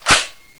ak47m_boltpull.wav